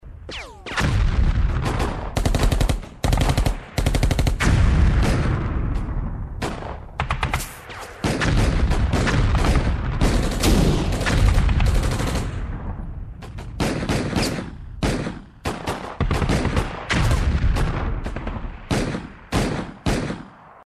Реалистичные эффекты с хорошей детализацией низких частот.
Пушечные залпы среди перестрелки